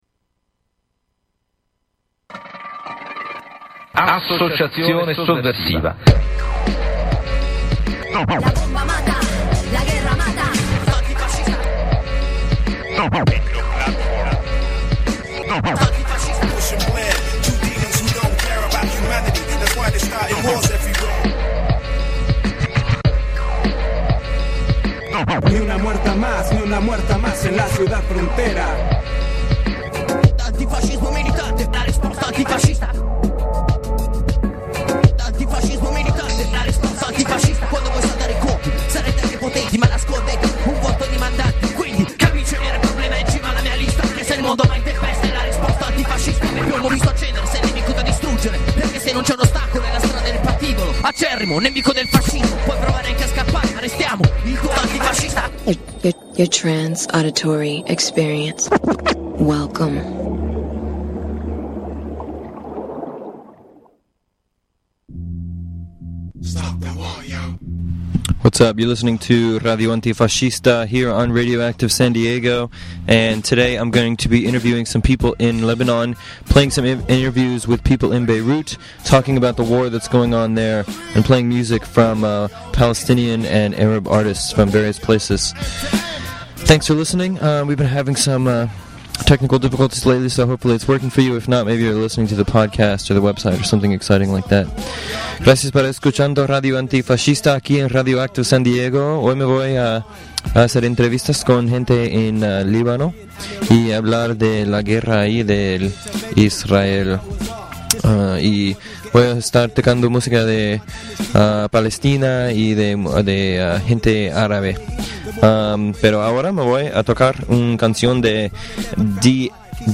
Civil Resistance in Lebanon and Arab Hip-Hop